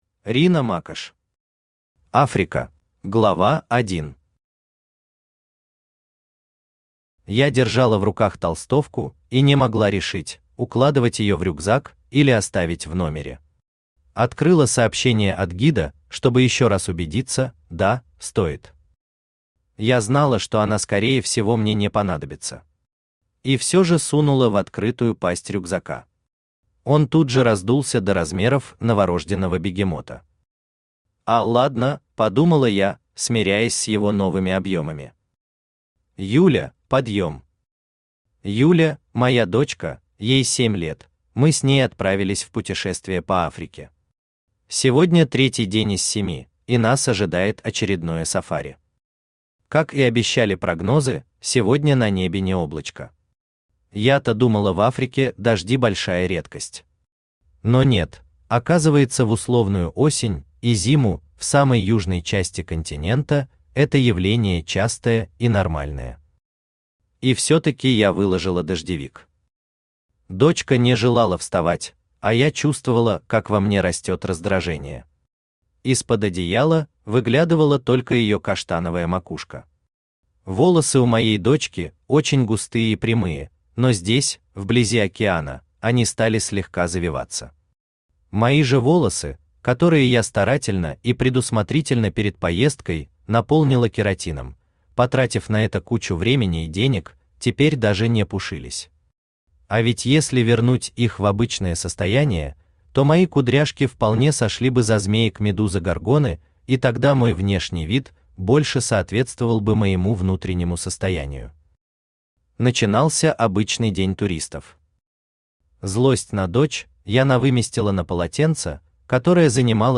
Аудиокнига Африка | Библиотека аудиокниг
Aудиокнига Африка Автор Рина Макошь Читает аудиокнигу Авточтец ЛитРес.